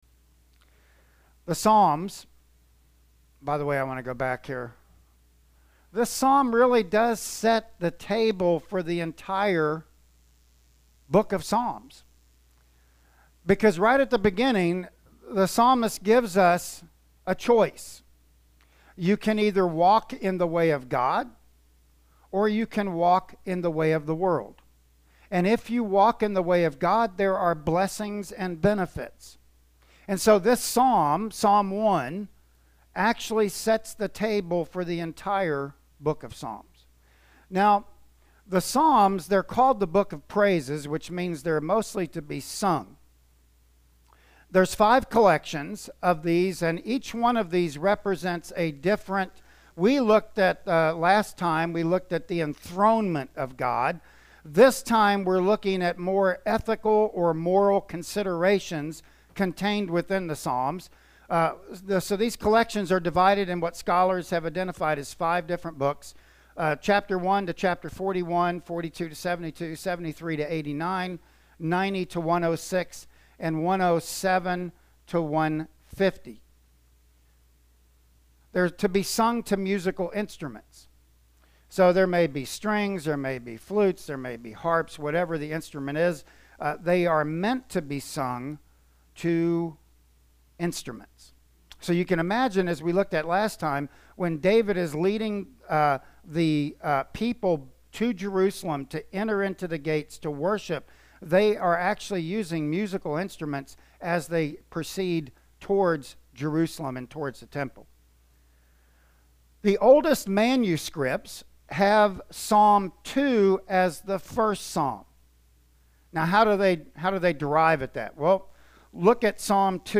Service Type: Sunday Morning Worship Service